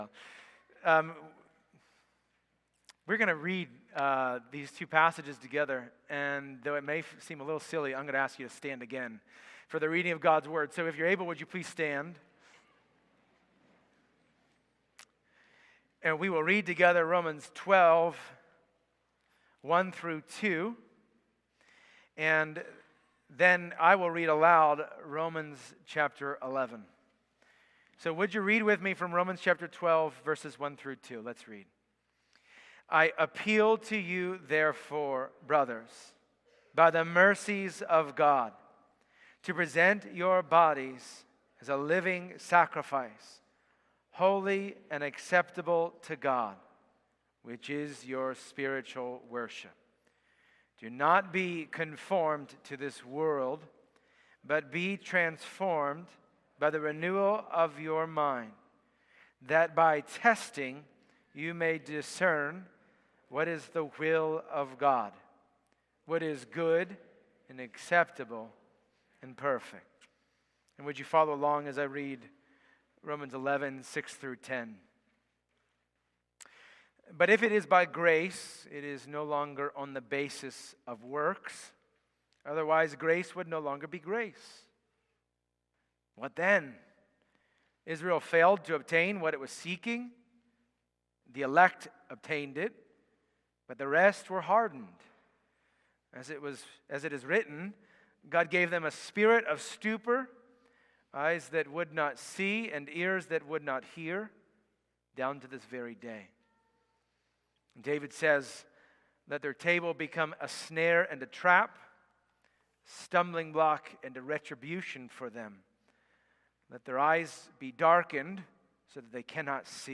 January-12-Worship-Service.mp3